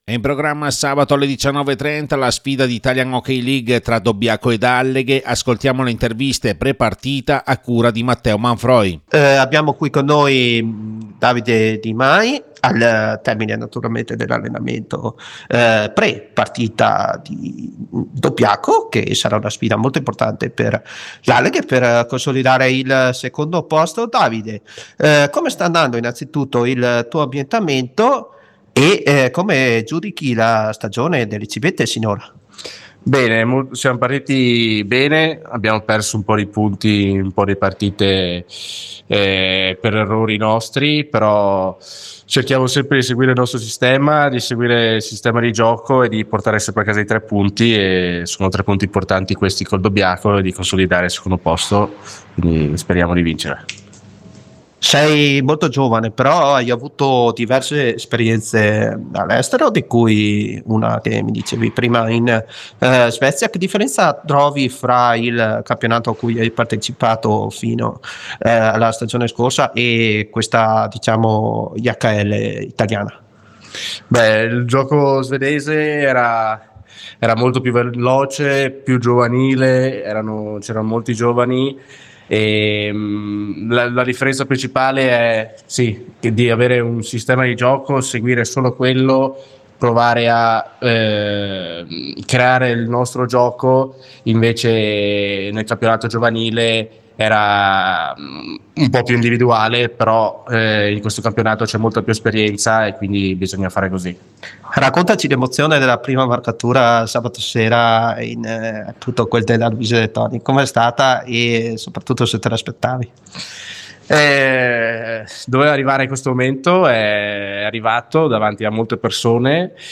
DOBBIACO – ALLEGHE: LE INTERVISTE PRE PARTITA